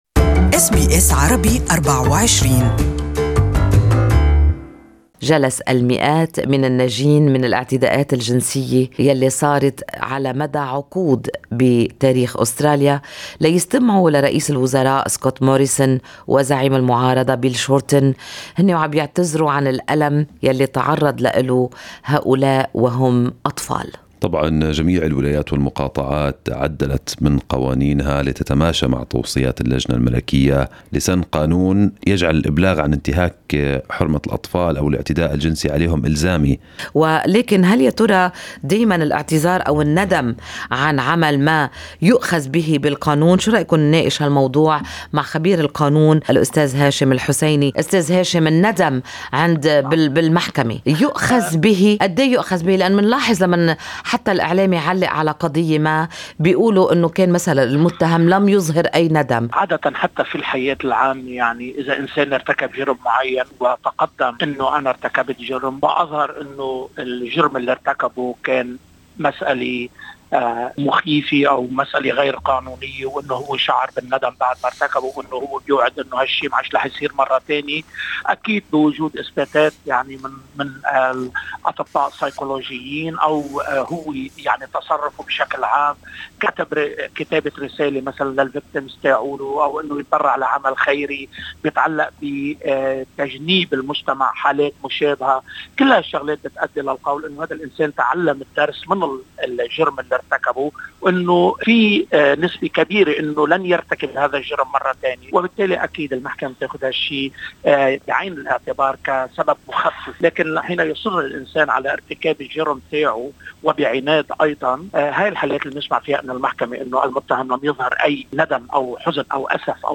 في حديث لراديو SBS عربي 24